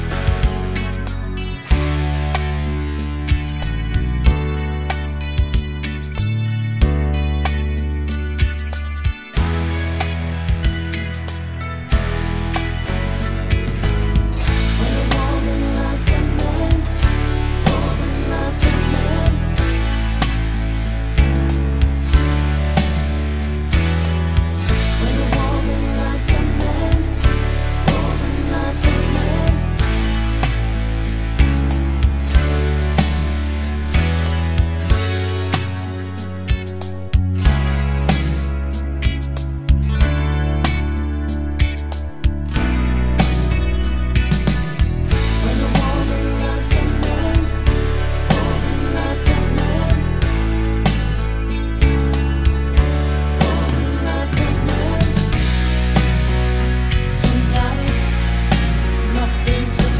no sax Soft Rock 4:17 Buy £1.50